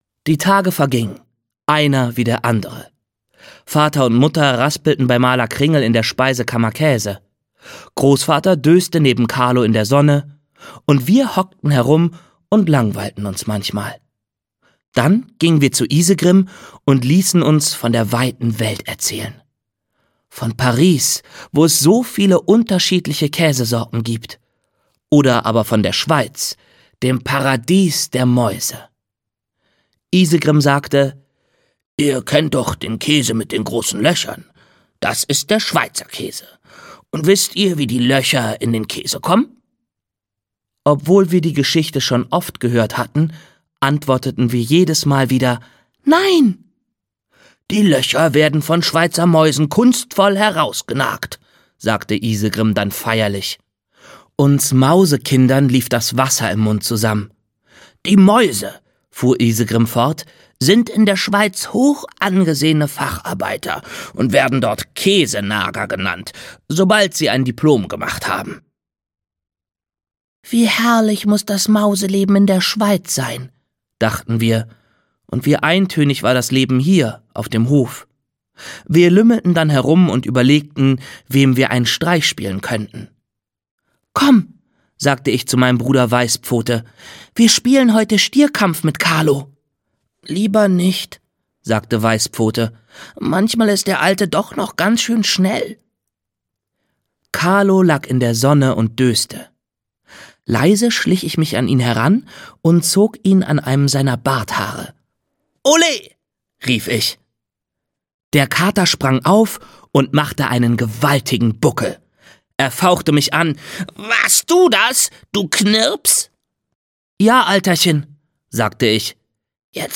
Die Zugmaus - Uwe Timm - Hörbuch